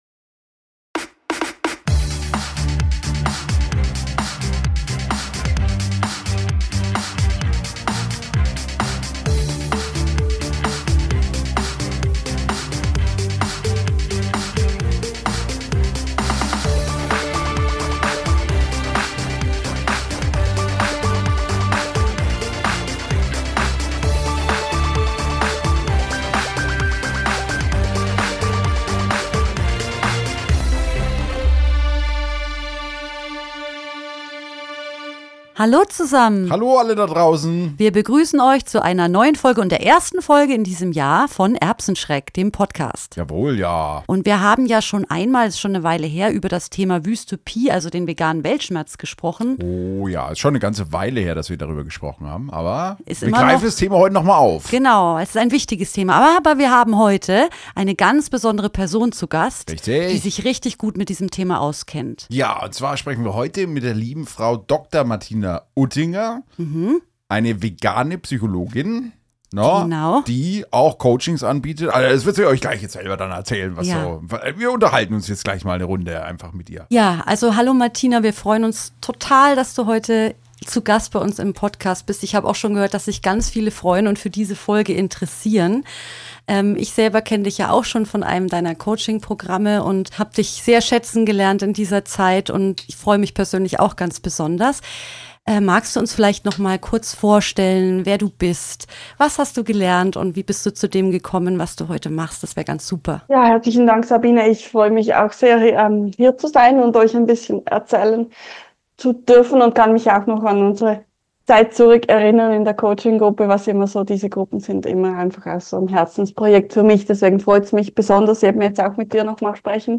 Dafür starten wir unseren Podcast in diesem Jahr mit einer tollen Person, die wir zu Gast haben durften.